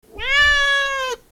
Cat Meowing Bouton sonore